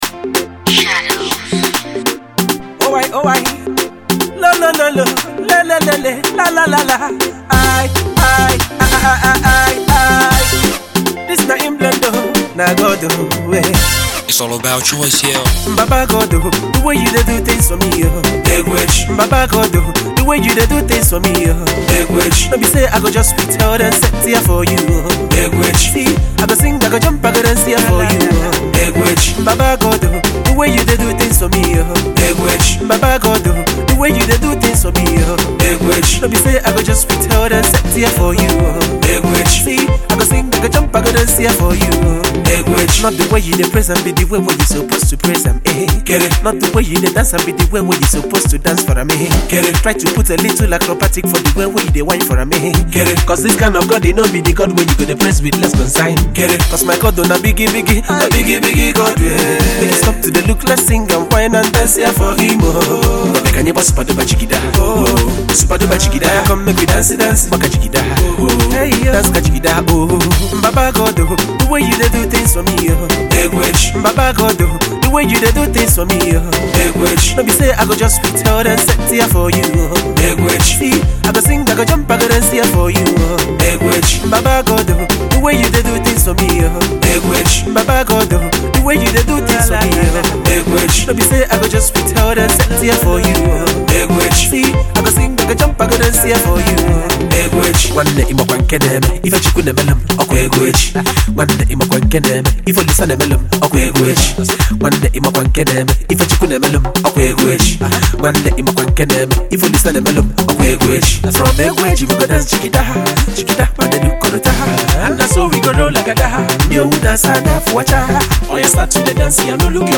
Pop single